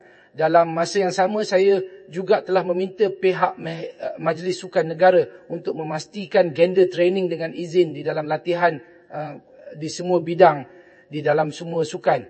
1b96b8b Malaysian-F5-TTS-v2